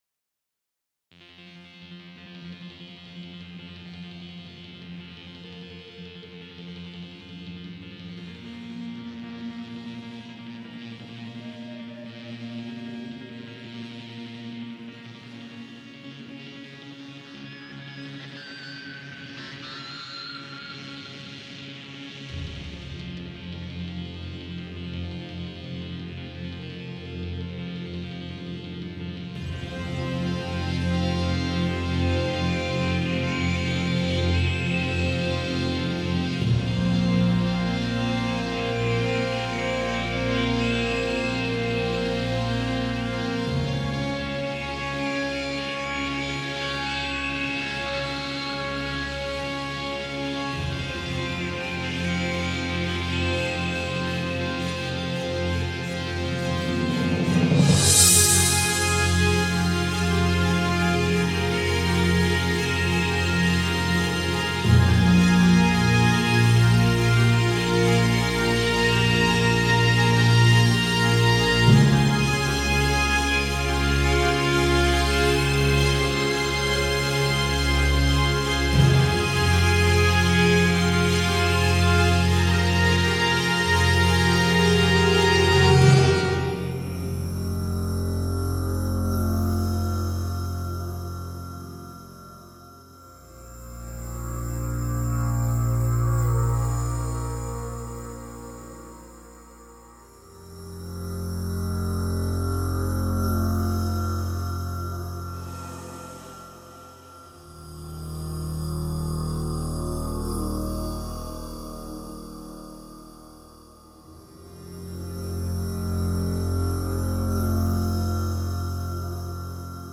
Cool, j'aime l'évolution du début en tout cas. On se met dans l'ambiance doucement, on a un côté un peu mystérieux et atmosphère qui appelle à la méfiance. Très sympas le mélange électro, orchestral, et une sorte de guitare (synthétique) qui donne un côté rock.
+ sonorité un peu froide qui met dans l'ambiance du thème
Une ambiance bien dark comme on les aime. Je vois bien des oiseaux en acier, et pourquoi pas aussi une traque. Bonne alternance entre les moments calmes et les moments plus rapides.